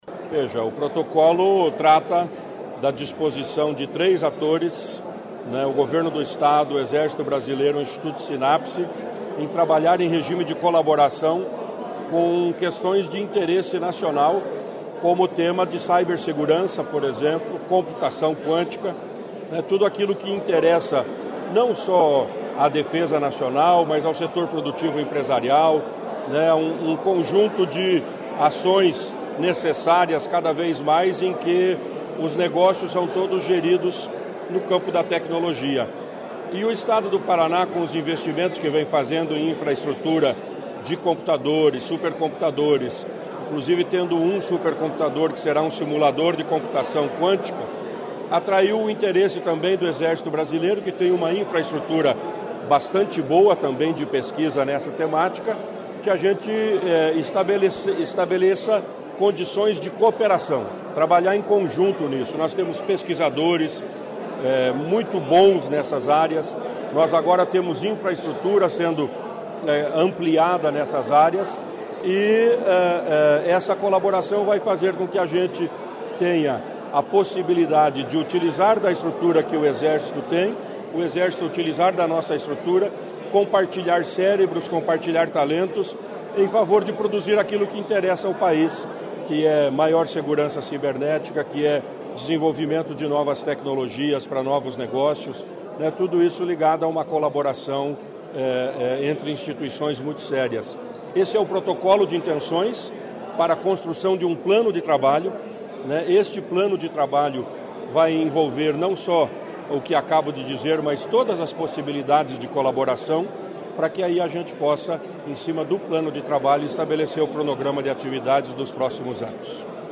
Sonora do secretário Estadual da Ciência, Tecnologia e Ensino Superior, Aldo Bona, sobre a parceria com Exército para pesquisas com IA e cibersegurança